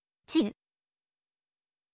chiyu